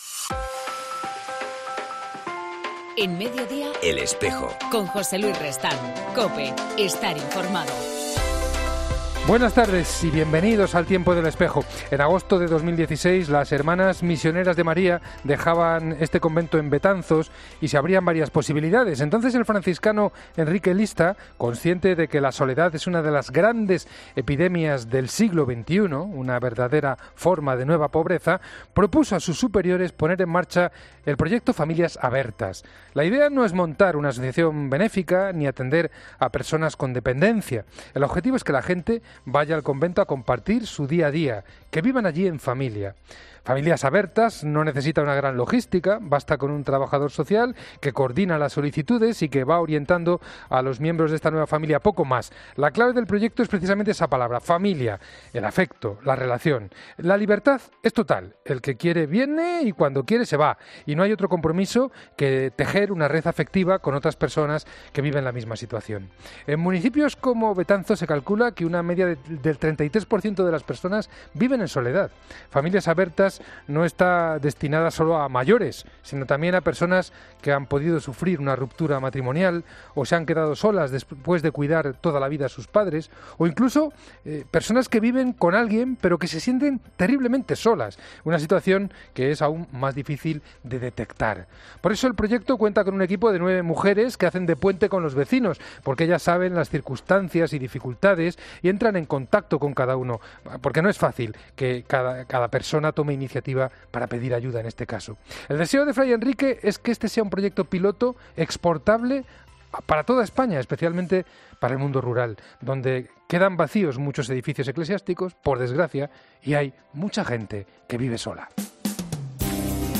En El Espejo del 15 de febrero hablamos con Eusebio Hernández, Obispo de Tarazona